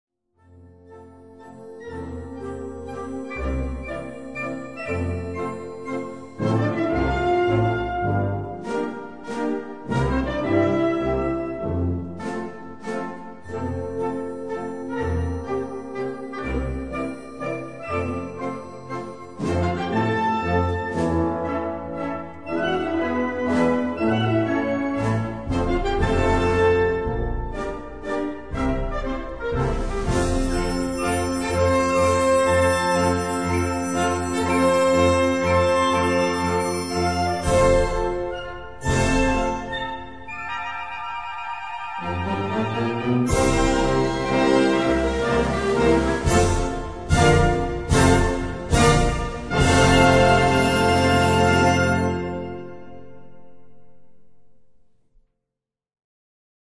& 13 Soprano